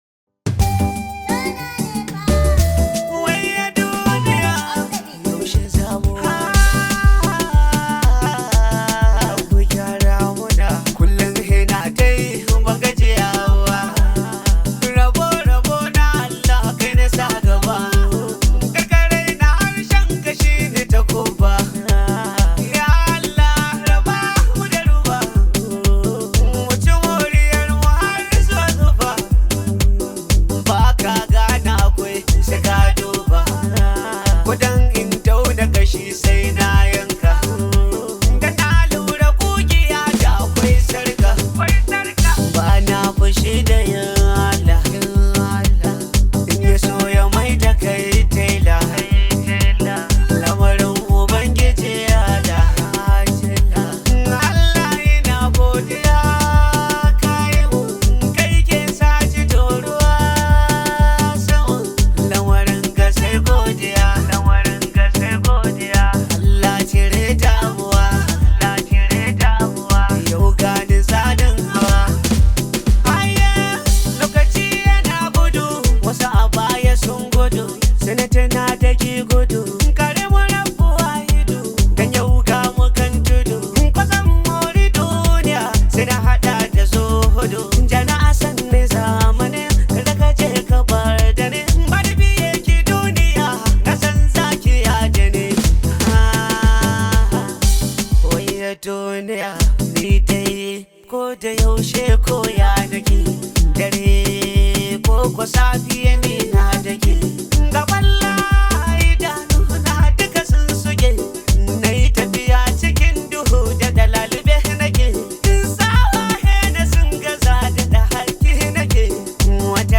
top rated Nigerian Hausa Music artist
high vibe hausa song